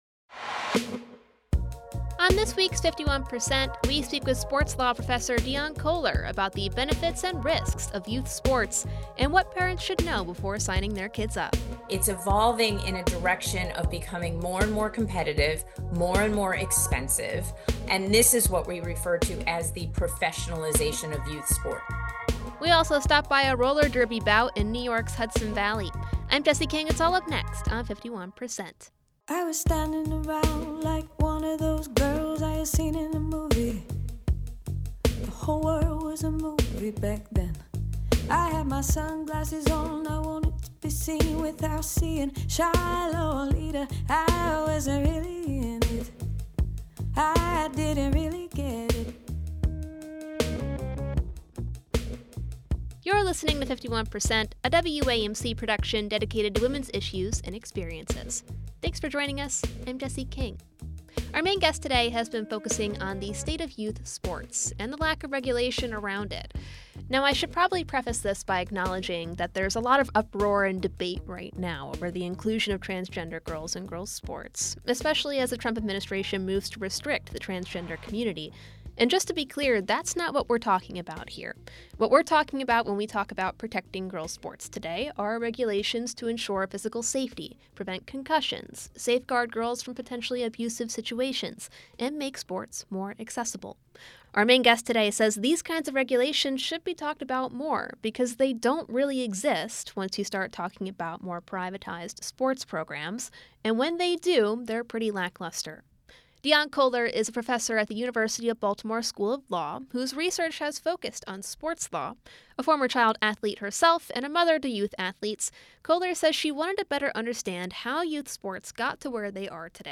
We also stop by a roller derby bout in New York's Hudson Valley.
51% is a national production of WAMC Northeast Public Radio in Albany, New York.